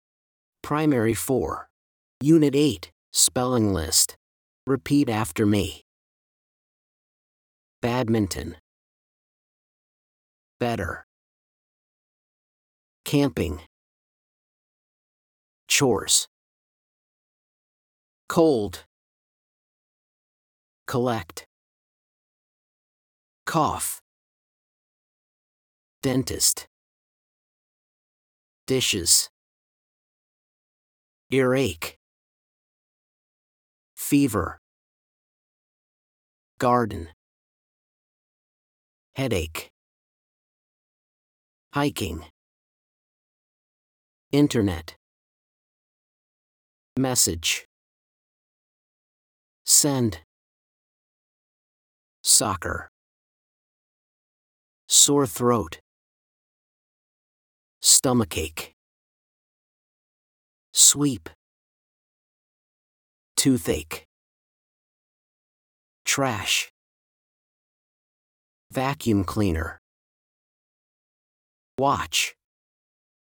blankThese are the words on the spelling list. Listen and repeat after the teacher: